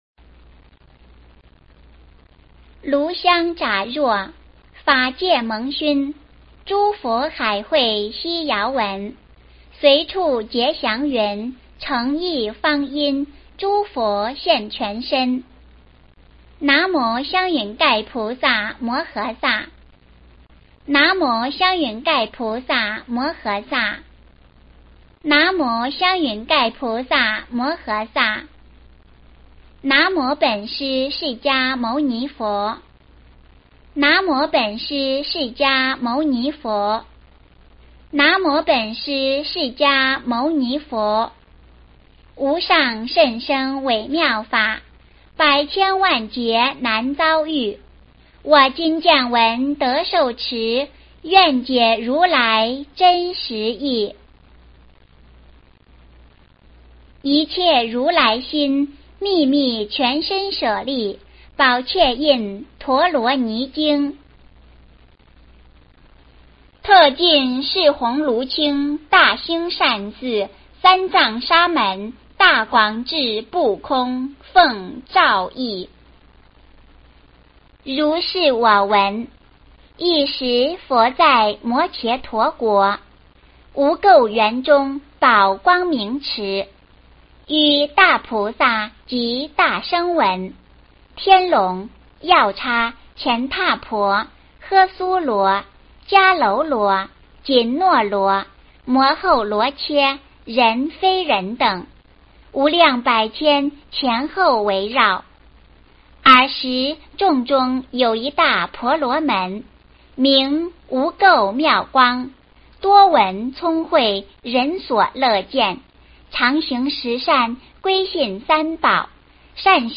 一切如来心秘密全身舍利宝箧印陀罗尼经 - 诵经 - 云佛论坛